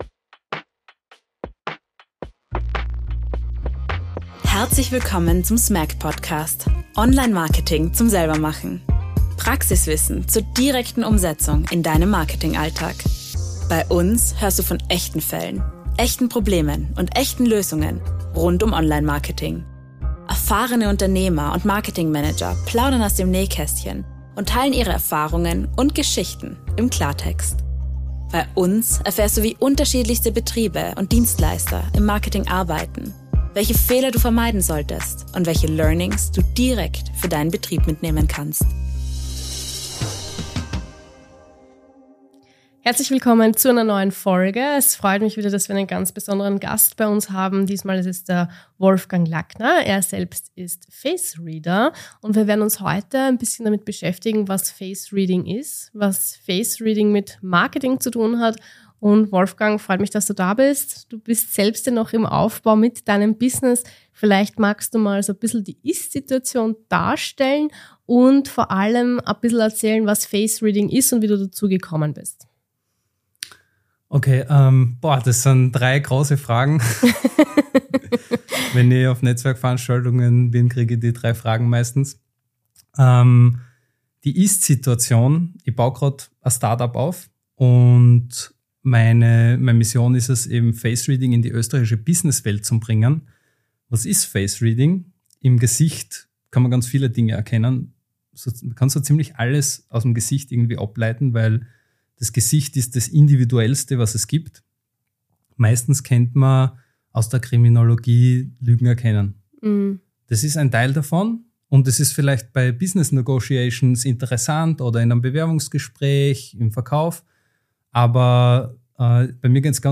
In der heutigen Podcastfolge haben wir einen ganz besonderen Gast bei uns im Studio.